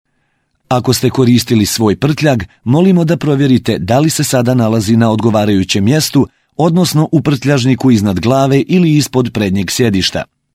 Bosnian – male – AK Studio